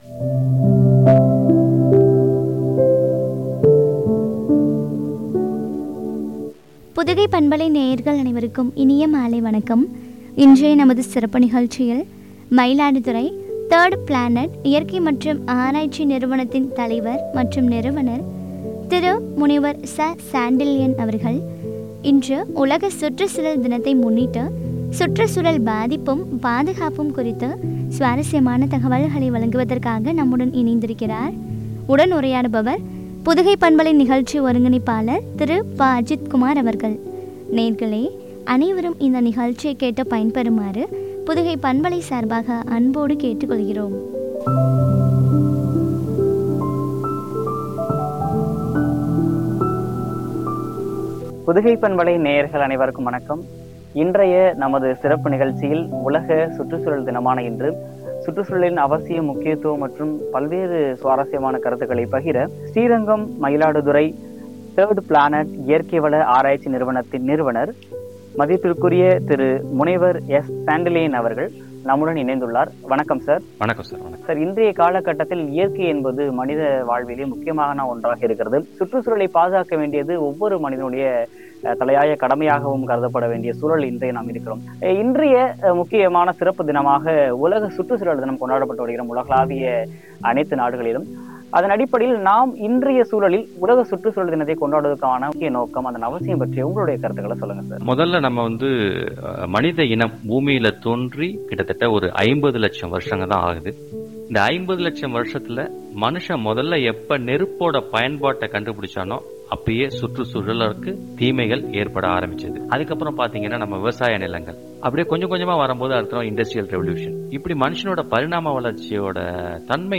பாதுகாப்பும் குறித்து வழங்கிய உரையாடல்.